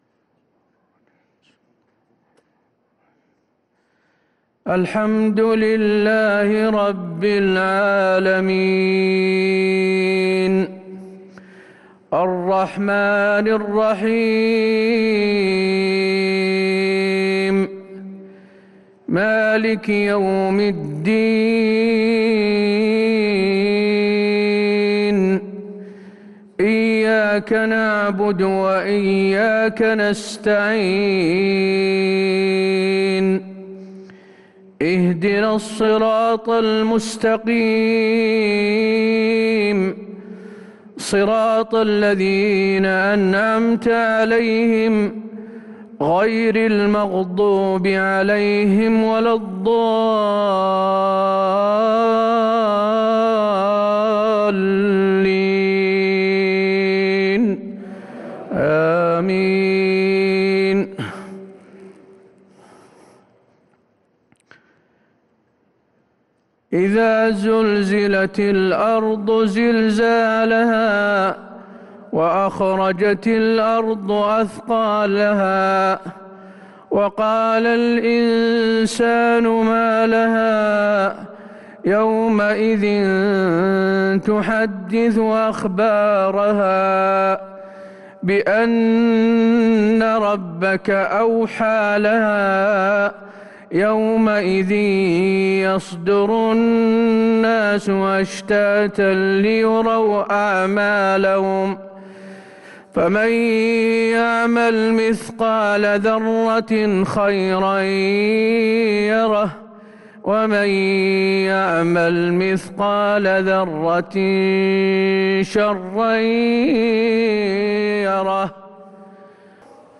صلاة المغرب للقارئ حسين آل الشيخ 25 ربيع الآخر 1443 هـ
تِلَاوَات الْحَرَمَيْن .